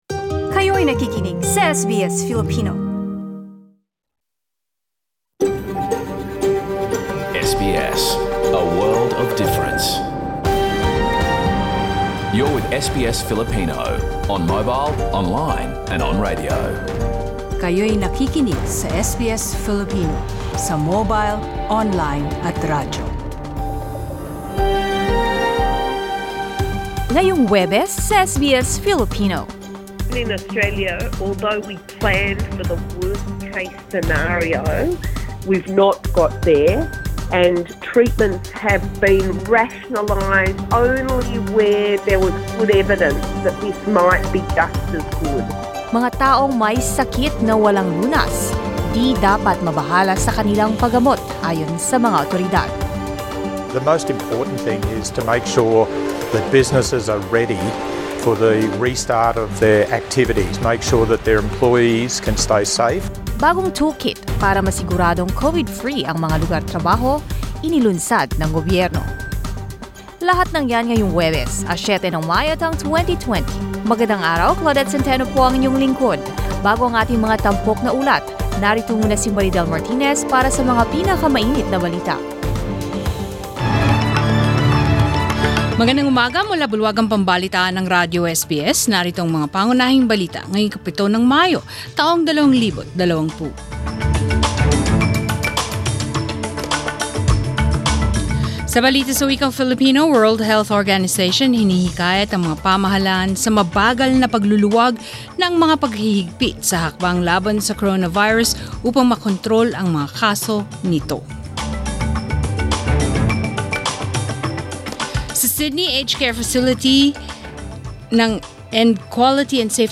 SBS news in Filipino, Thursday 7 May